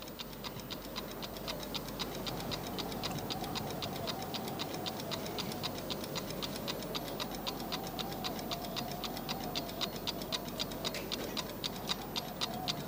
ticking.mp3